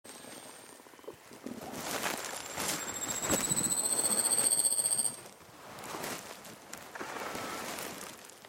ウ グ イ ス 属   ヤ ブ サ メ １　1-04-03
鳴 き 声：晩夏にはチャッ、チャッというウグイスに似た声で鳴く。
鳴き声１
yabusame01.mp3